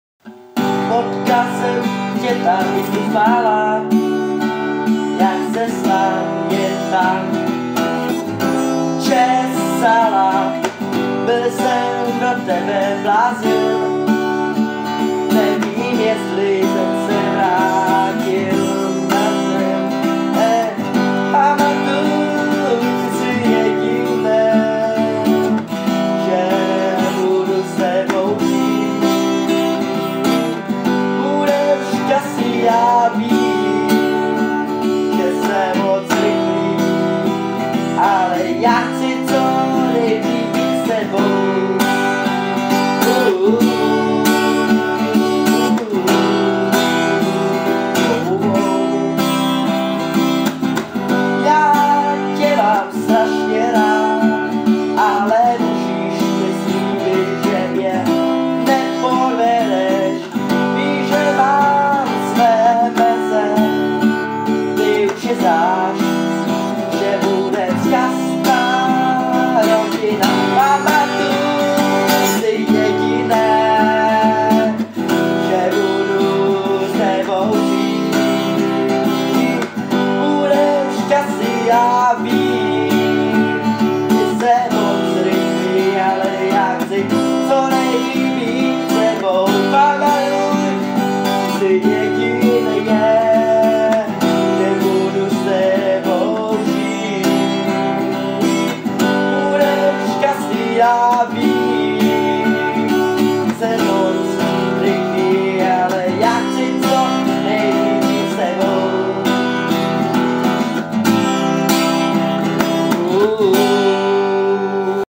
Žánr: World music/Ethno/Folk
Folkové hudební album